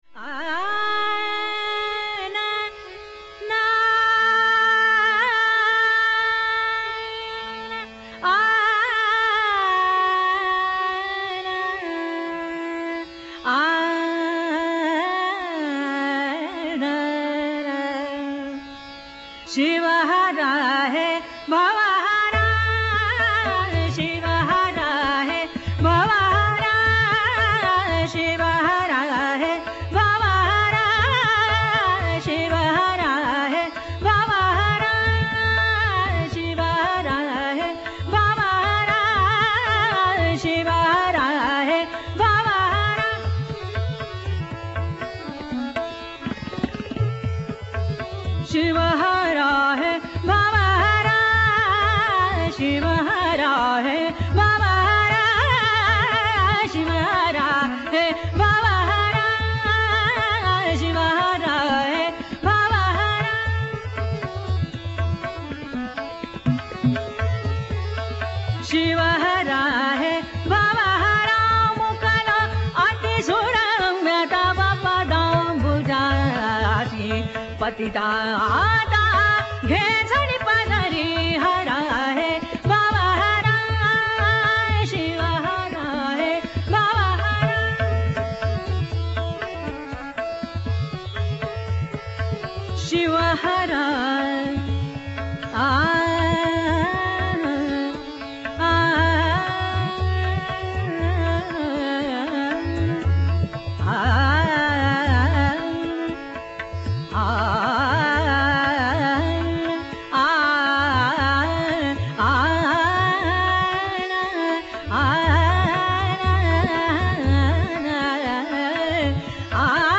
An old Marathi bandish by